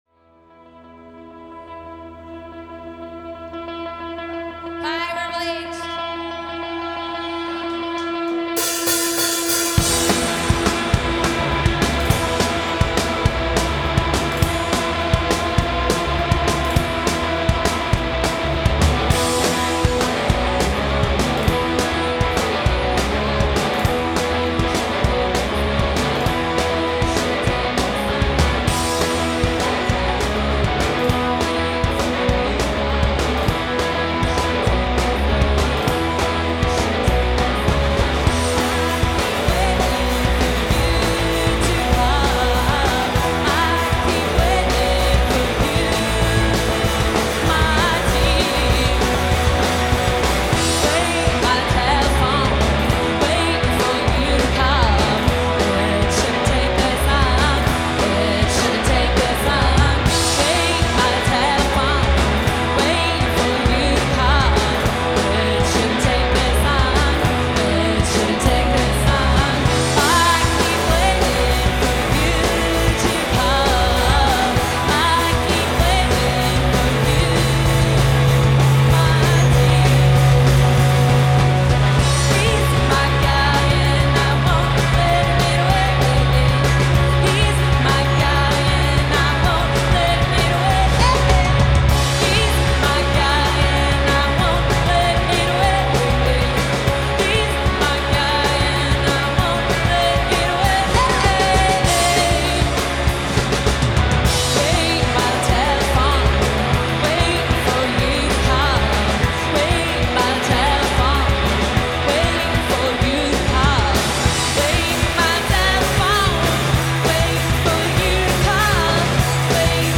Amsterdam Paradiso
post–Punk Rock band